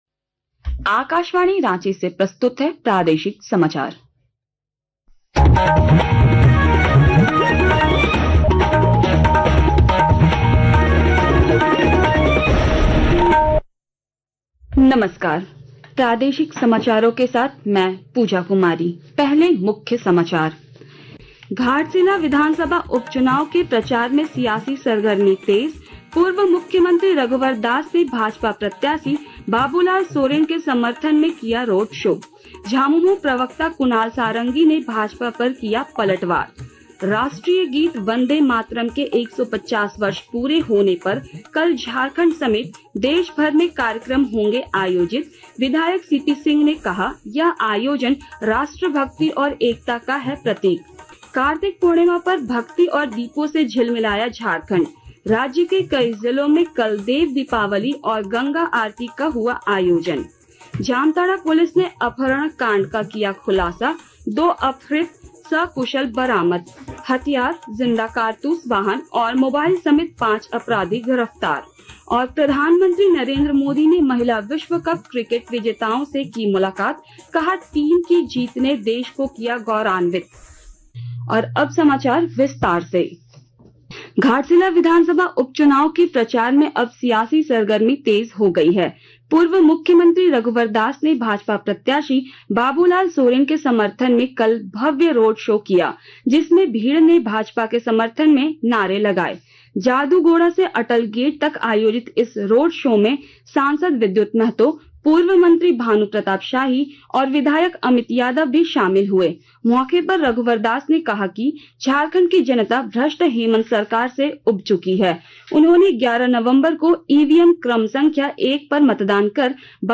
Transcript summary Play Audio Evening News